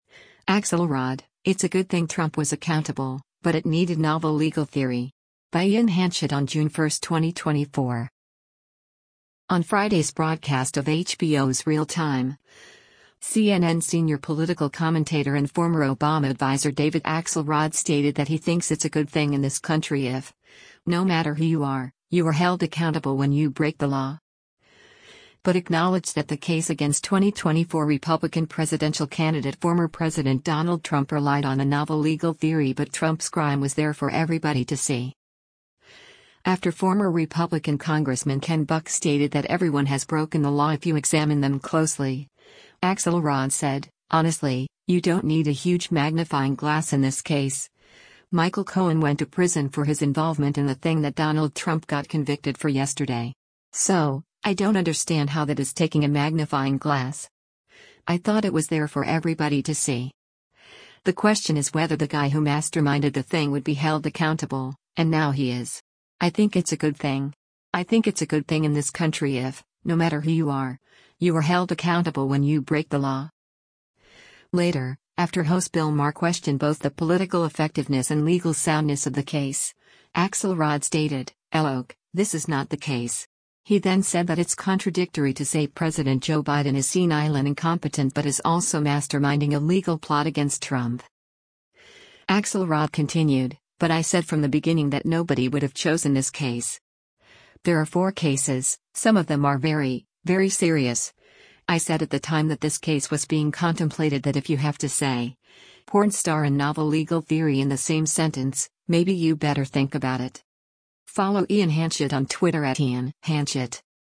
On Friday’s broadcast of HBO’s “Real Time,” CNN Senior Political Commentator and former Obama adviser David Axelrod stated that he thinks “it’s a good thing in this country if, no matter who you are, you are held accountable when you break the law.”
Later, after host Bill Maher questioned both the political effectiveness and legal soundness of the case, Axelrod stated, “[L]ook, this is not the case.”